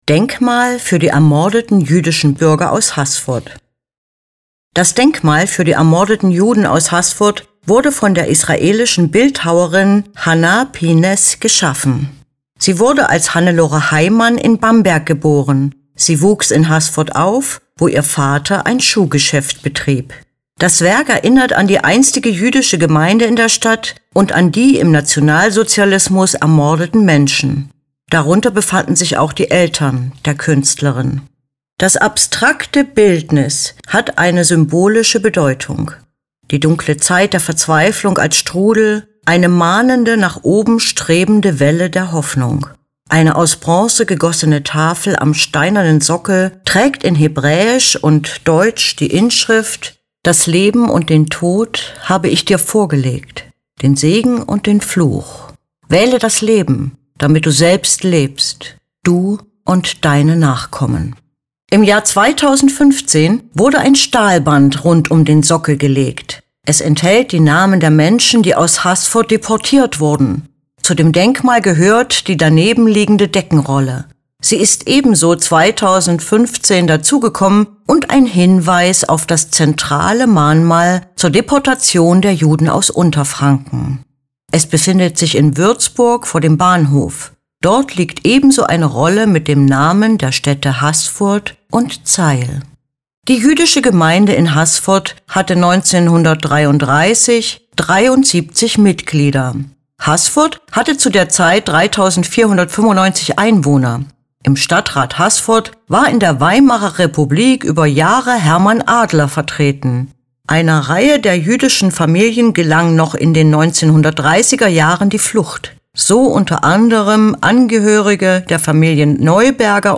Lassen Sie sich die Infos zu diesem Werk einfach vorlesen.